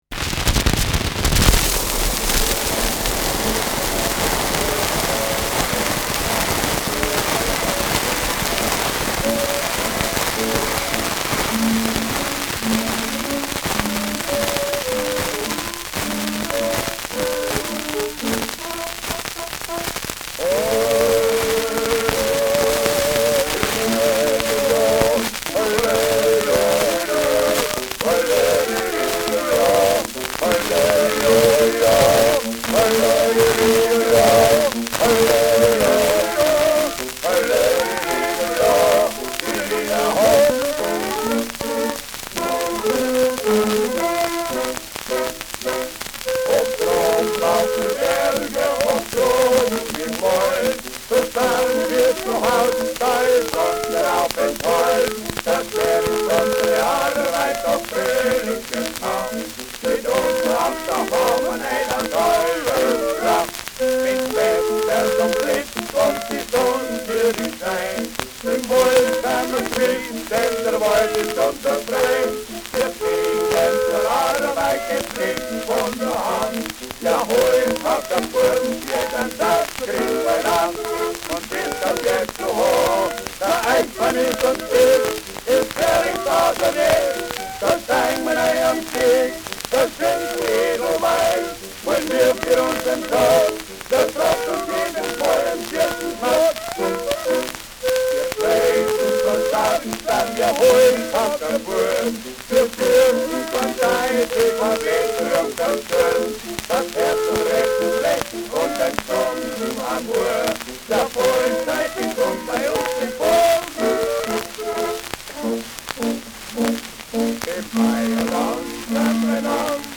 Schellackplatte
[Nürnberg] (Aufnahmeort)